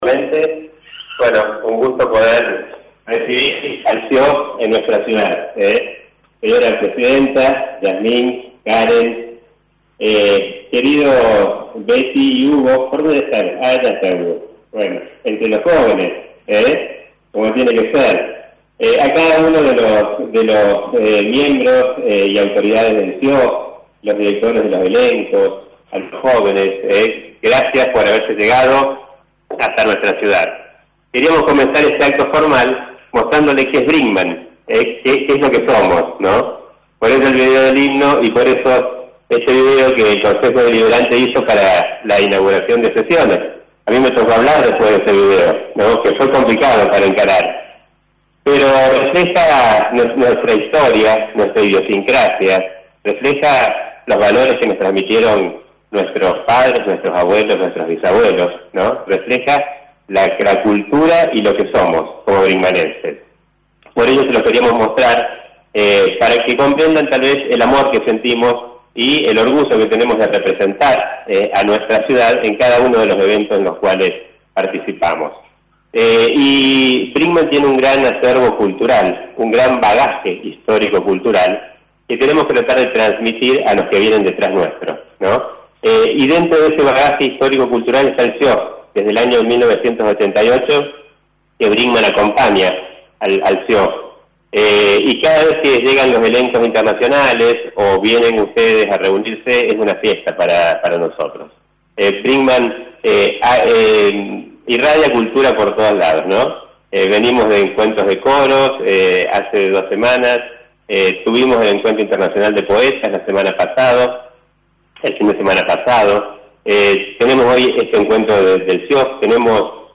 El intendente Municipal de Brinkmann Dr. Gustavo Tevez dio la bienvenida y agradeció a todas las personas que durante la pandemia continuaron trabajando para que este intercambio cultural no frene.